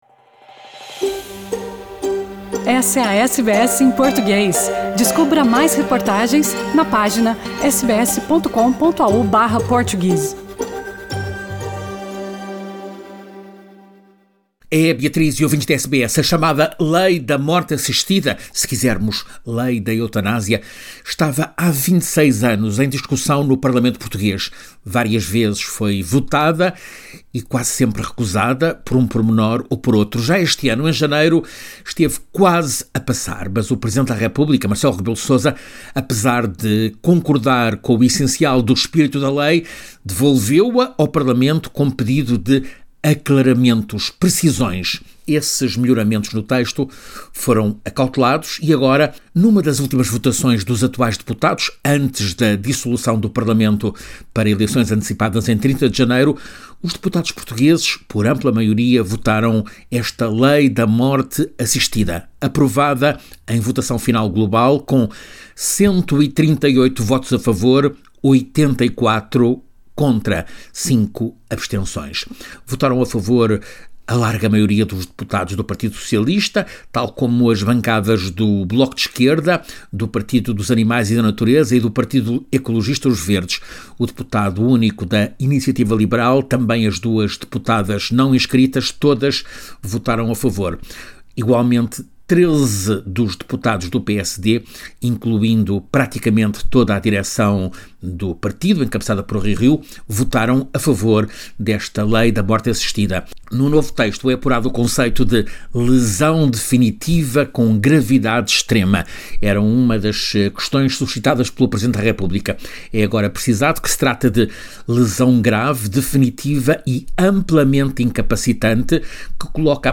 crônica